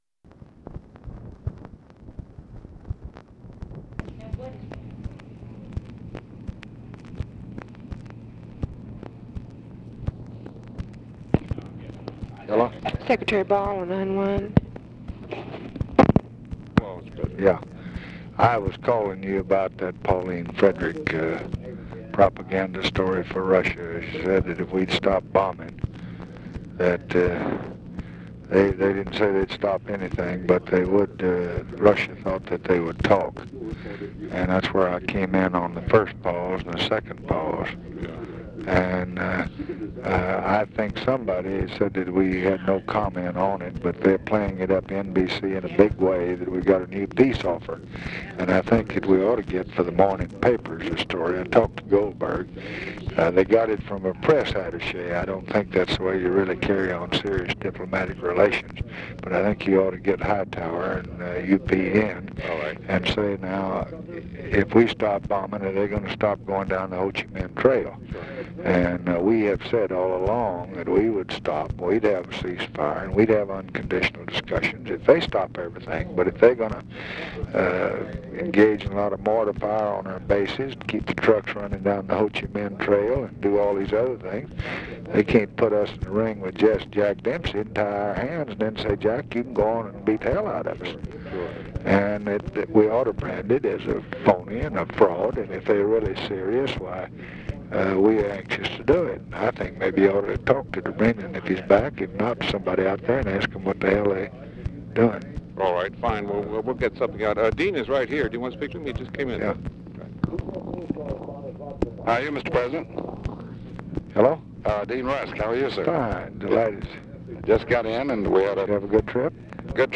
Conversation with GEORGE BALL and DEAN RUSK, April 23, 1966
Secret White House Tapes